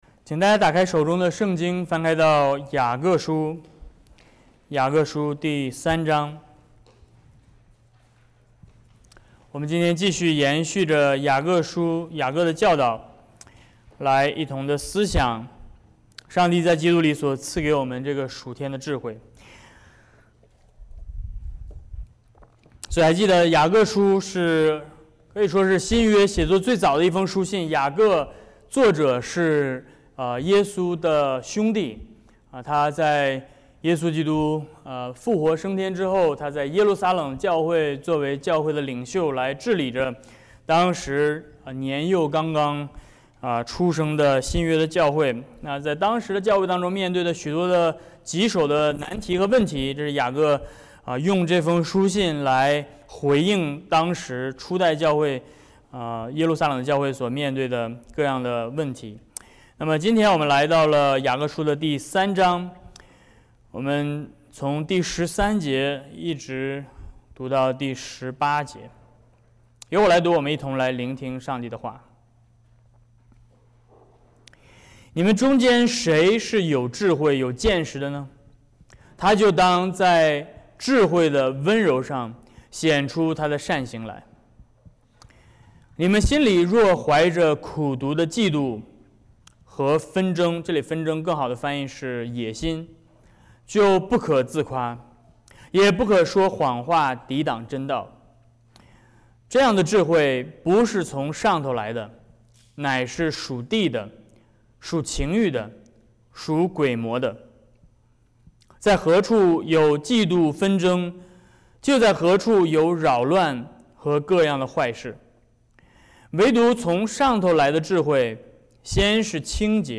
雅各书 Service Type: 主日讲道 Date：June 23，2024｜主日25 本周讲道讨论问题： 1. 雅各提到了两种不同的智慧，分别是什么？